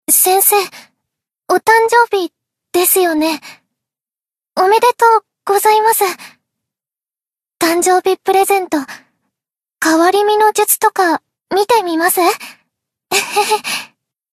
贡献 ） 分类:蔚蓝档案语音 协议:Copyright 您不可以覆盖此文件。
BA_V_Tsukuyo_Season_Birthday_Player.ogg